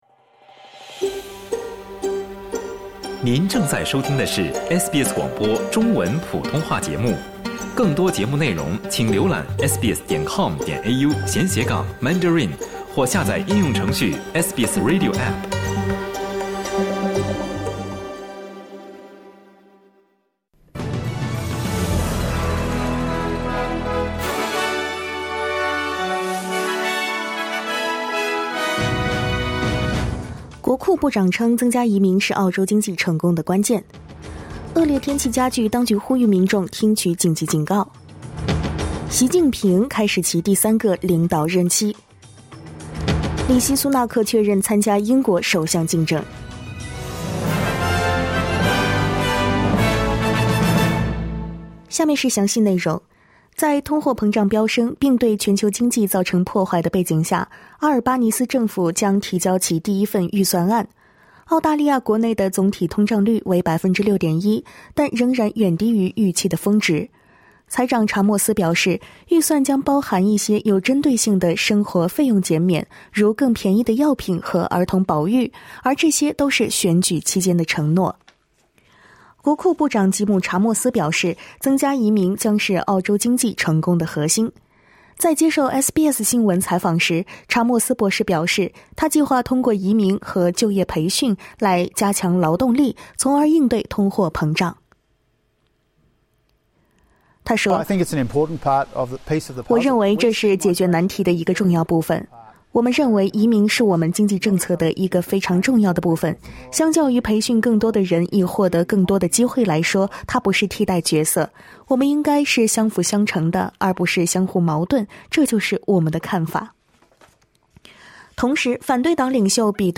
SBS早新闻（10月24日）
请点击收听SBS普通话为您带来的最新新闻内容。